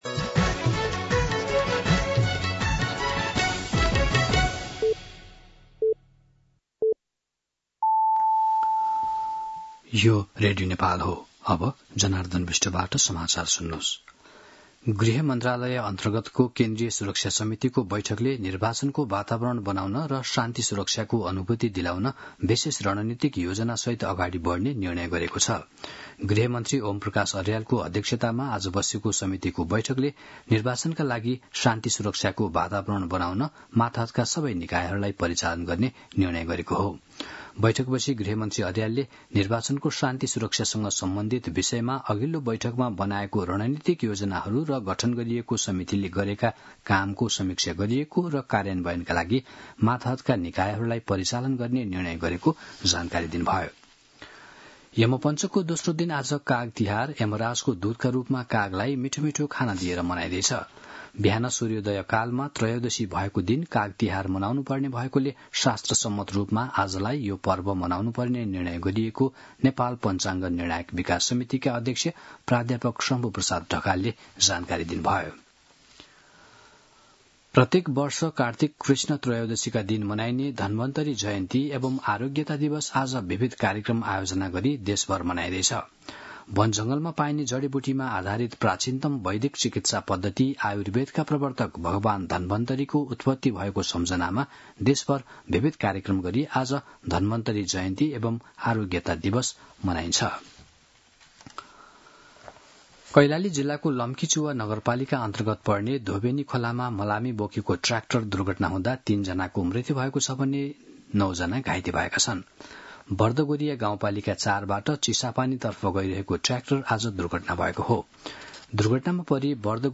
दिउँसो ४ बजेको नेपाली समाचार : २ कार्तिक , २०८२
4-pm-Nepali-News-9.mp3